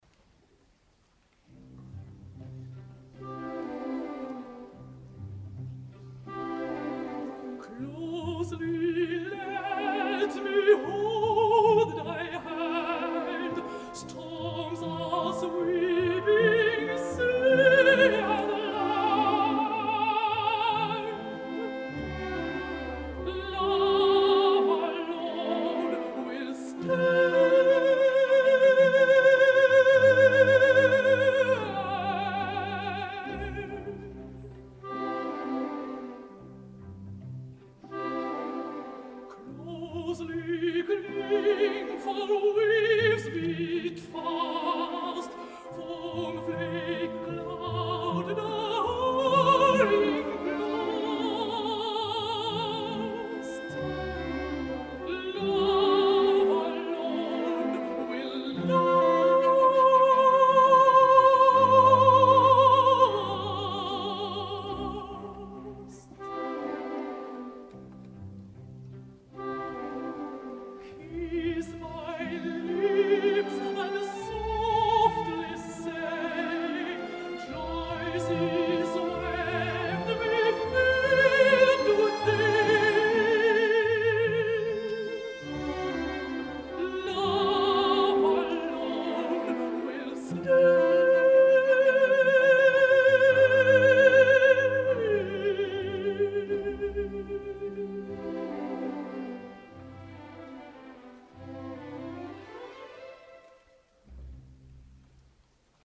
El passat 16 de desembre moria a Huizen (Holanda) la contralt Aafje Heynis.
Arran de la seva mort m’ha arribat la gravació radiofònica que avui porto a IFL.